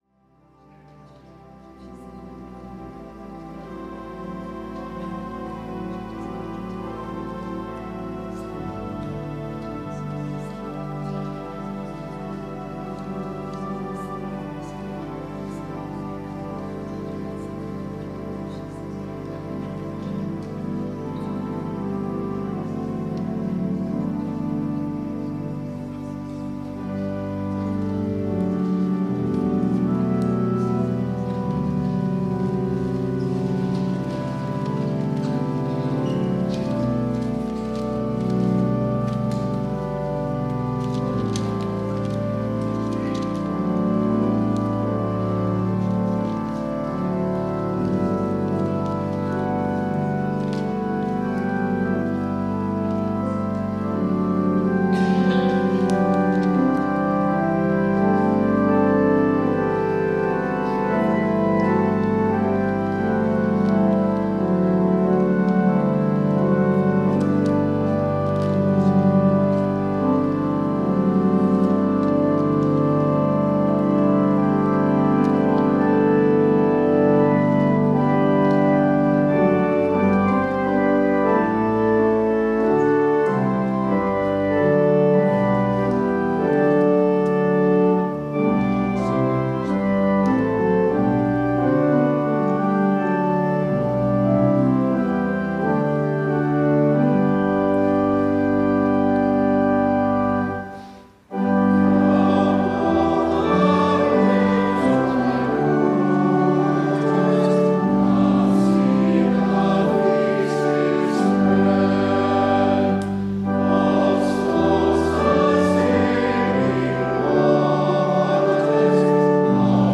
WORSHIP - 10:30 a.m. Third Sunday in Lent